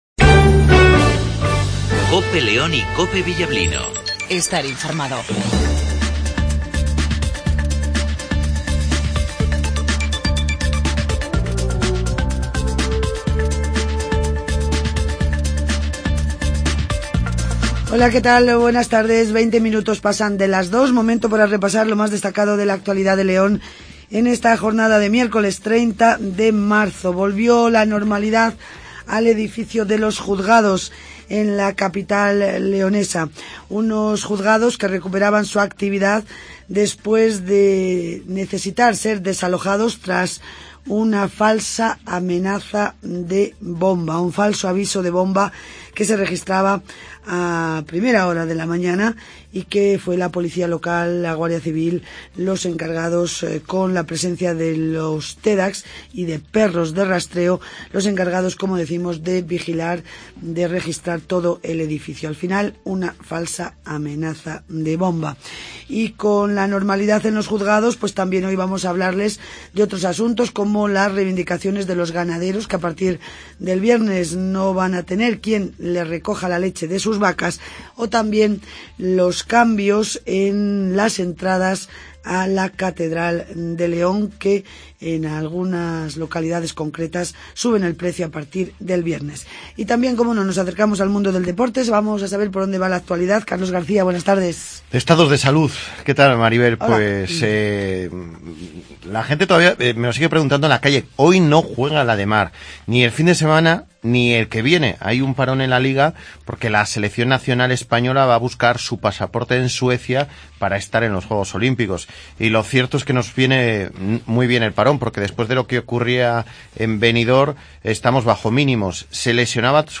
INFORMATIVO MEDIODIA
antonio silván ( Alcalde de León )